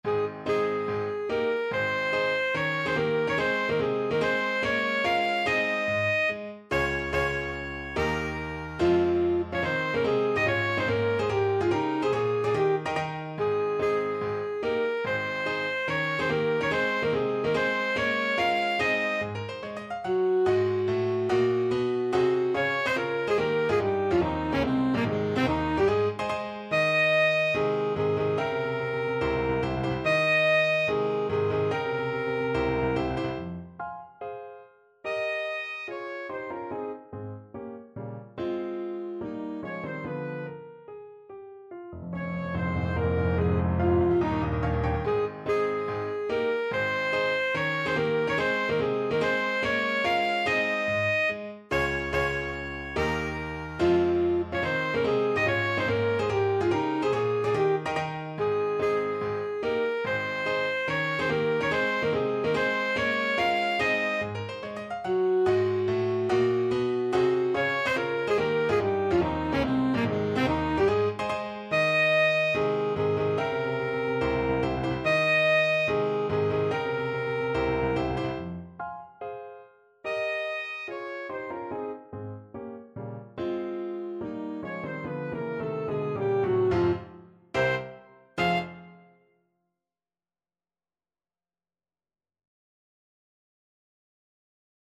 Alto Saxophone
Allegro non troppo (=72) (View more music marked Allegro)
4/4 (View more 4/4 Music)
Classical (View more Classical Saxophone Music)